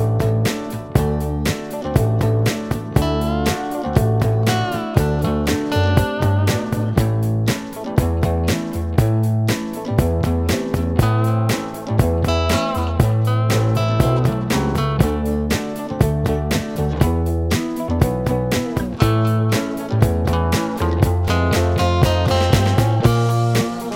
no Backing Vocals Country (Female) 3:08 Buy £1.50